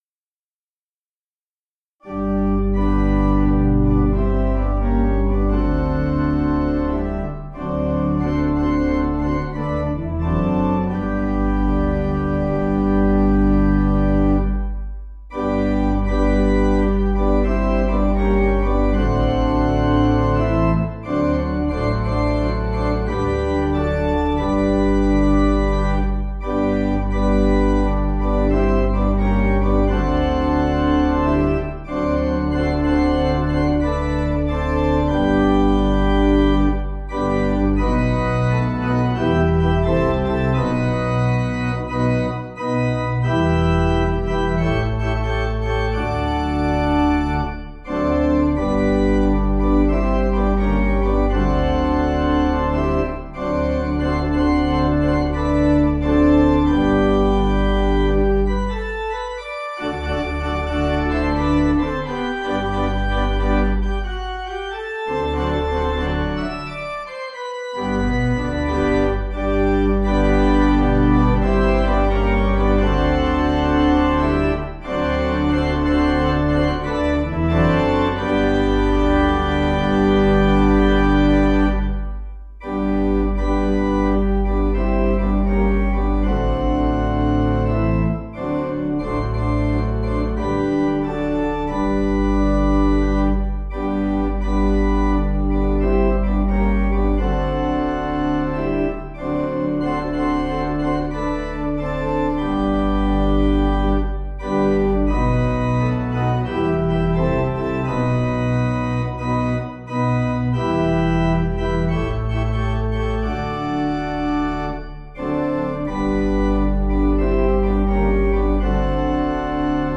Audio files: MIDI,
Composer: J. G. Crabbe
Key: A♭ Major